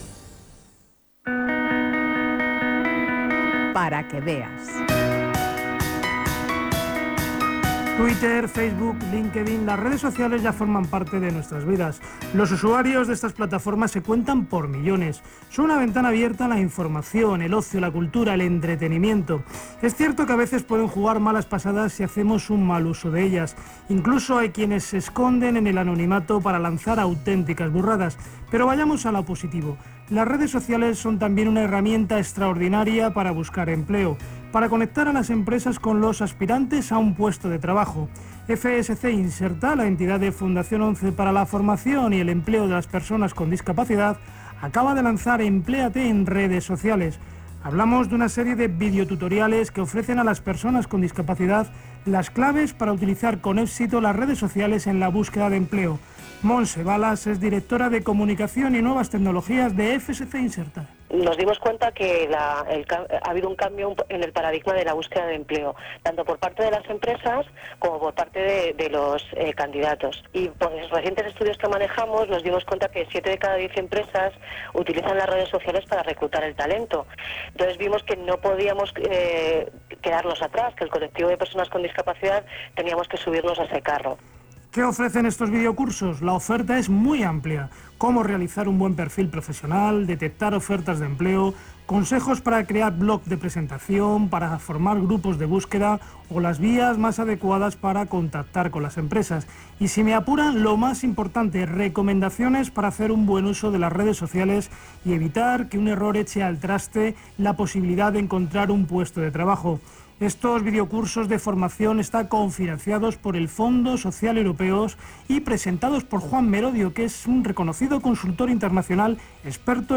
Crónica sobre la iniciativa en Radio 5, con declaraciones de responsables de Fundación ONCE y buscadores de empleo con discapacidad